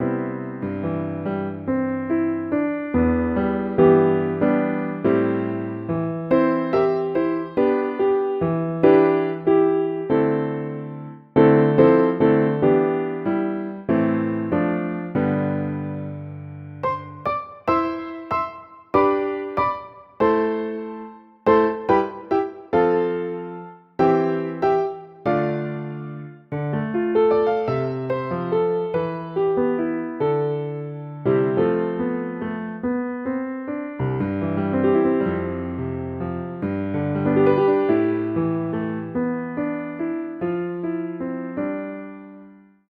Piano 2 (bucle)
piano
melodía
repetitivo
rítmico